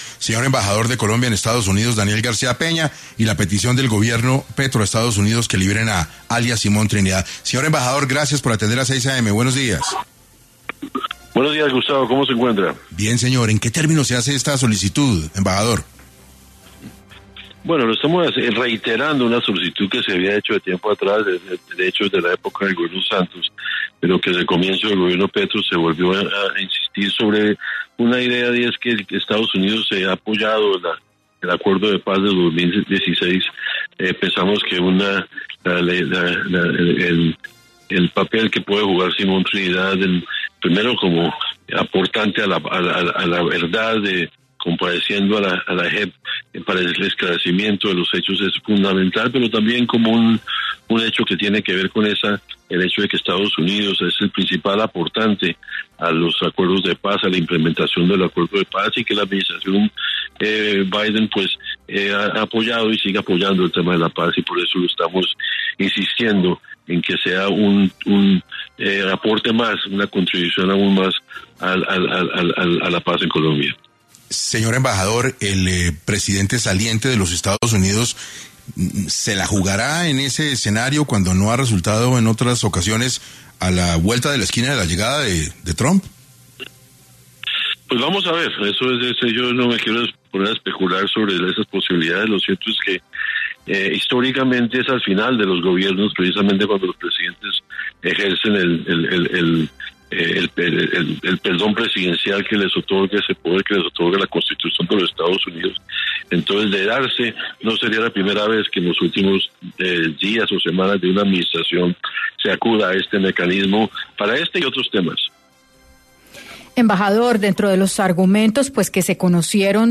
En Caracol Radio estuvo Daniel García Peña, embajador de Colombia en Estados Unidos